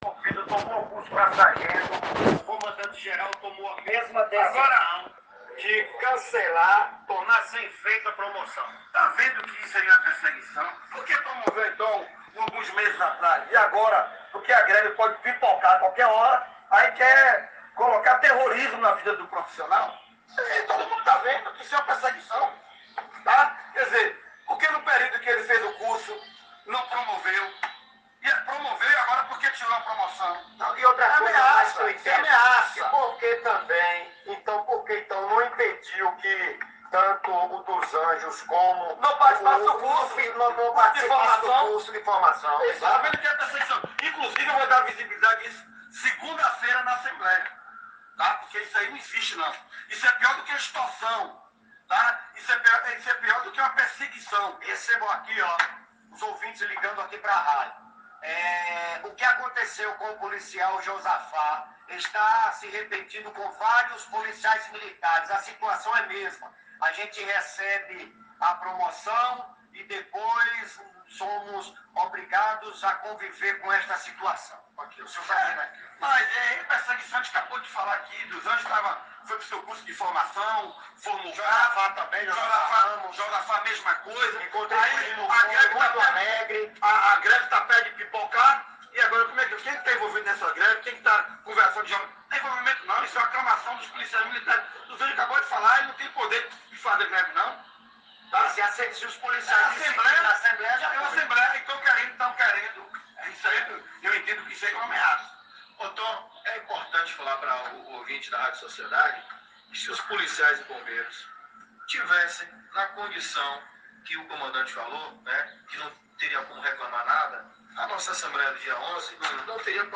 As declarações do deputado Tom, foram feitas durante o Programa Nas Ruas e na Policia